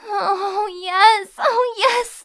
moan10.ogg